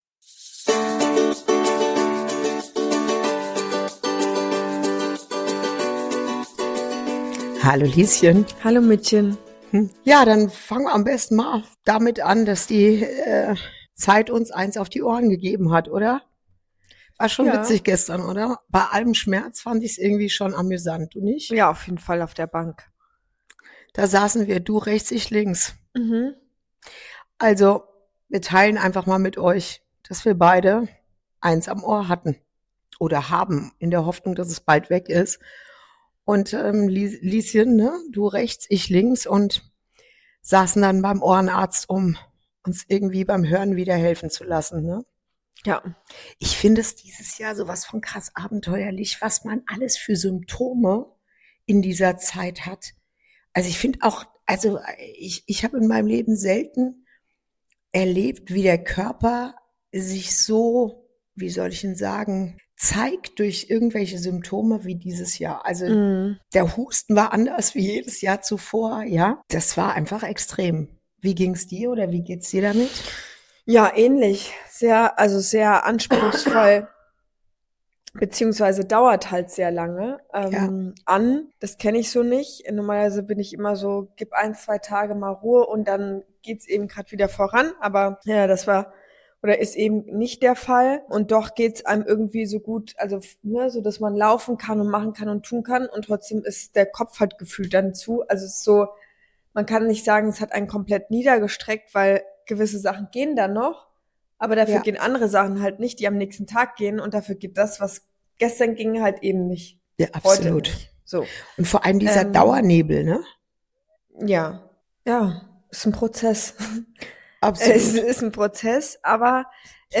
Ehrlich, verletzlich und mit einem Augenzwinkern sprechen die beiden über körperliche Prozesse, mentale Disziplin, das Annehmen von Hilfe – und warum wahre Nähe oft dort beginnt, wo wir aufhören, perfekt sein zu wollen. Ein Gespräch über Verbindung, Veränderung und die Kraft, einfach zu sagen, was man wirklich fühlt.